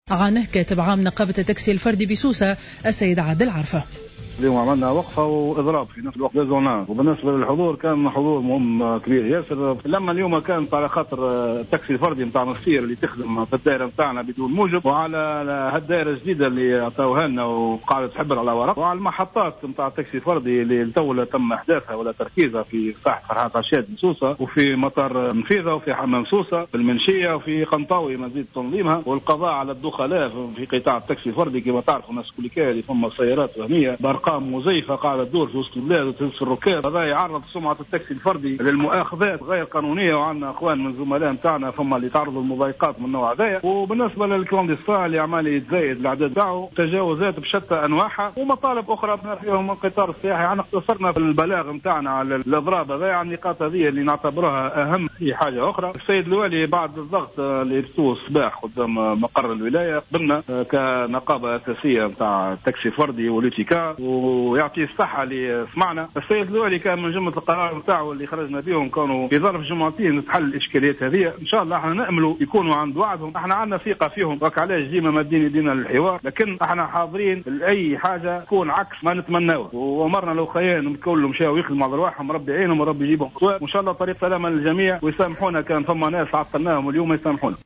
على هامش الوقفة الاحتجاجية التي نفذها اليوم أصحاب سيارات التاكسي الفردي بسوسة أمام مقر الولاية